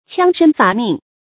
戕身伐命 注音： ㄑㄧㄤ ㄕㄣ ㄈㄚˊ ㄇㄧㄥˋ 讀音讀法： 意思解釋： 傷害身體。